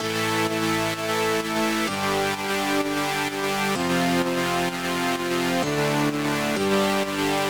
VDE 128BPM Notice Bass 3 Root A SC.wav